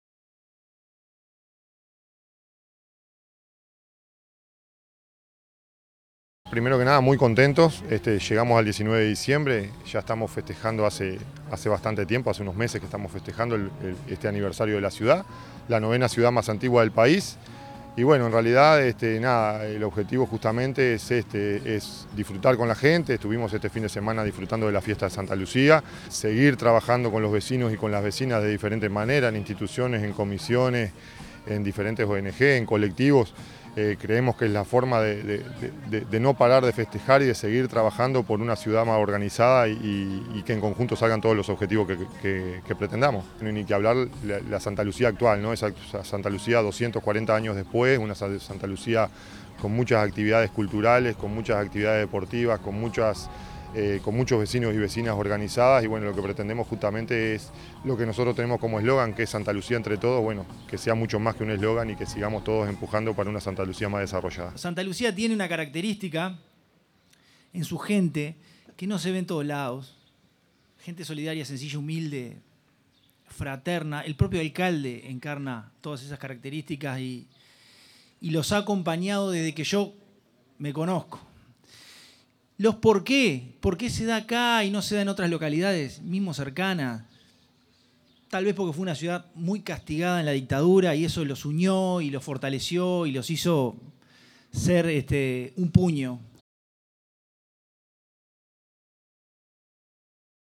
Con la presencia de autoridades departamentales, locales, niñas y niños, la ciudad de Santa Lucía celebró su 240° aniversario.
francisco_legnani_secretario_general_4.mp3